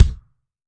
B.B KICK13.wav